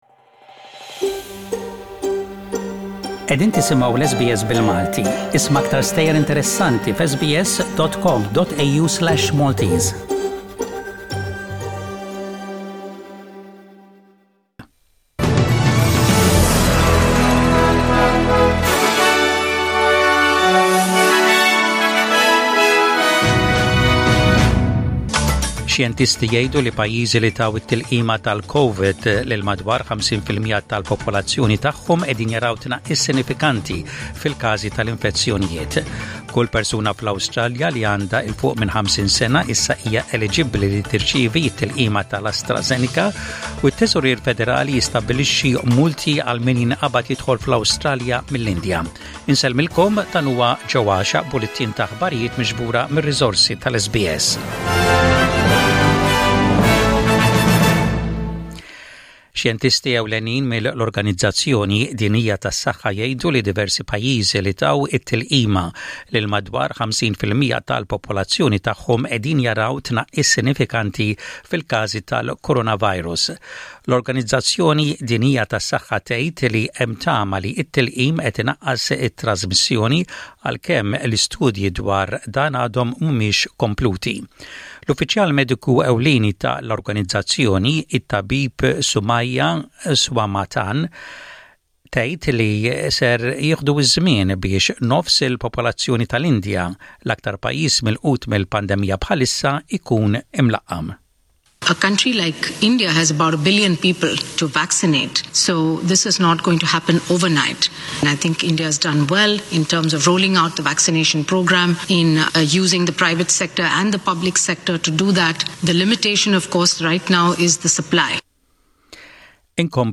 SBS Radio | Maltese News: 04/05/21 | SBS Maltese